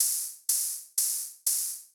ENE Beat - Open Hats.wav